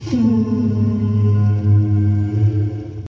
conch-mp3.mp3